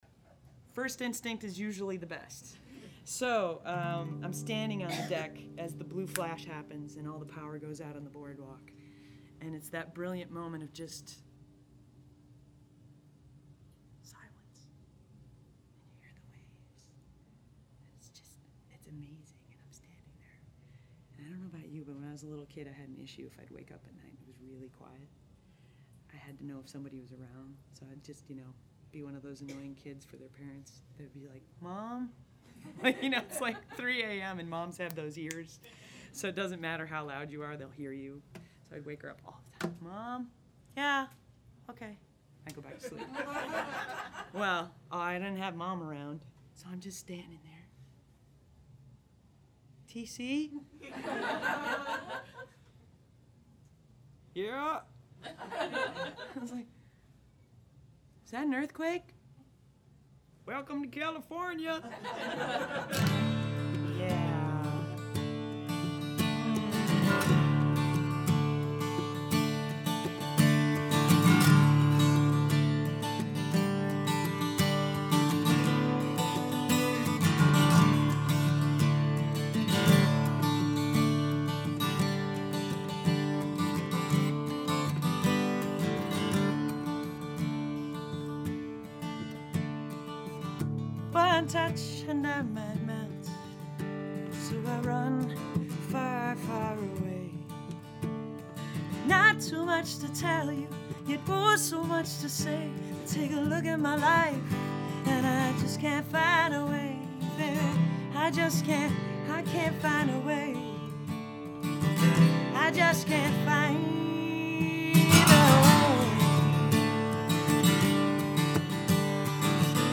I was pretty light on the NR - This is a very dynamic recording and it's going to stay that way.
Artifacts are (IMHO) light in all cases, but it's pretty clear to me that NR on each track is the right way to go.
nr_on_mix_dry.mp3